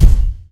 menuhit.ogg